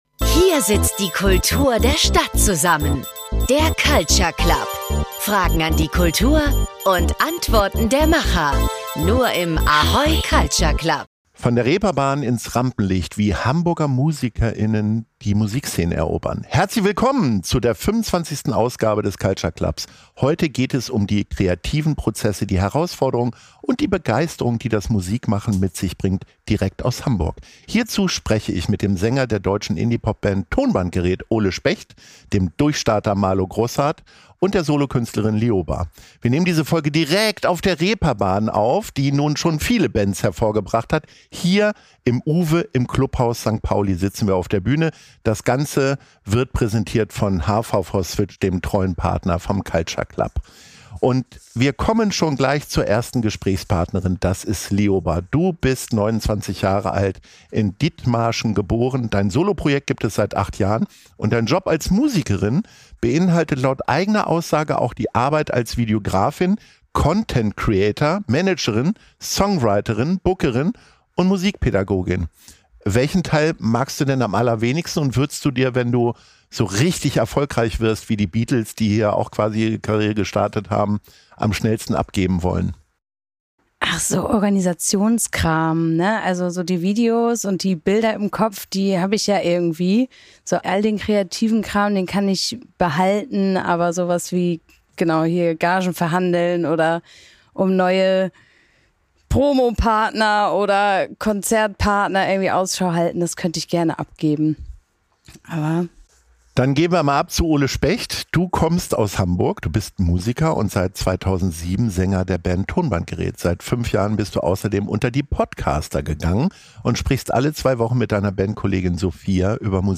Aufgenommen wurde diese Folge im Herzen von St. Pauli – dem Klubhaus St. Pauli auf der Reeperbahn bei UWE. Wie können junge Musikerinnen und Musiker in Hamburg besser gefördert werden?